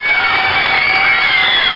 Girls Sound Effect
girls.mp3